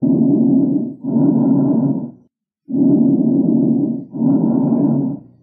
download) Clear Lung Sounds.mp3 .
Clear_Lung_Sounds.mp3